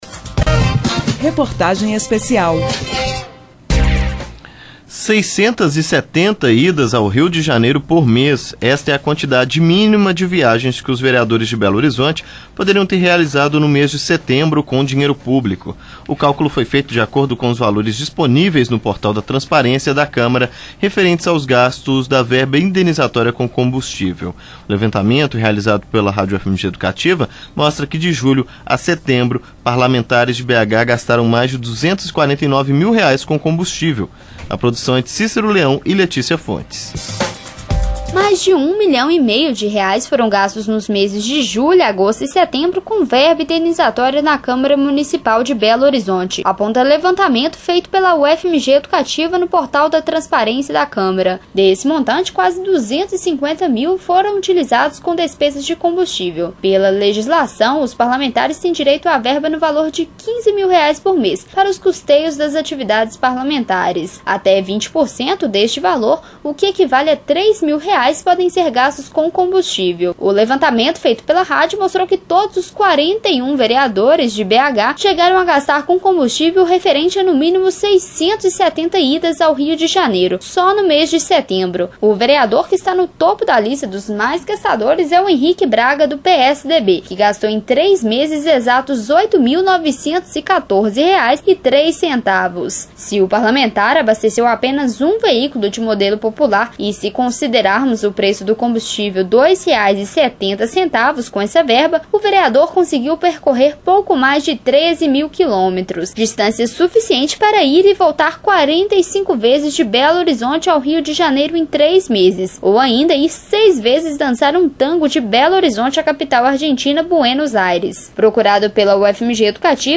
Reportagem Especial B: Imagine viajar mais de 650 vezes por ano para o Rio de Janeiro?